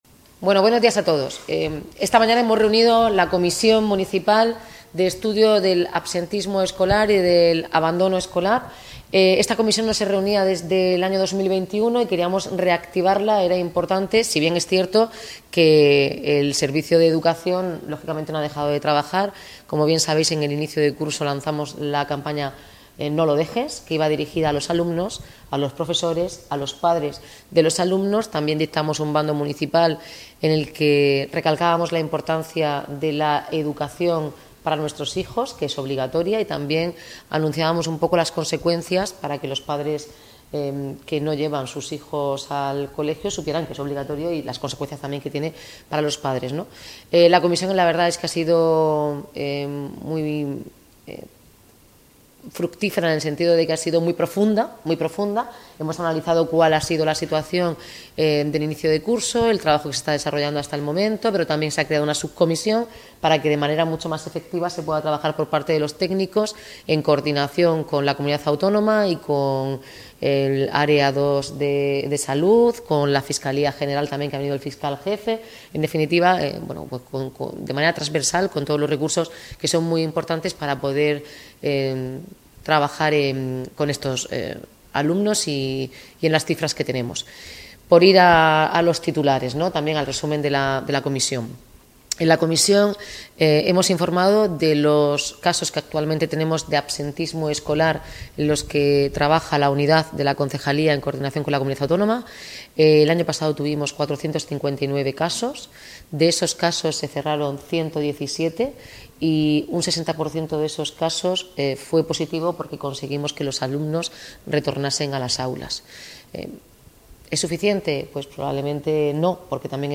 Enlace a Declaraciones de la alcaldesa Noelia Arroyo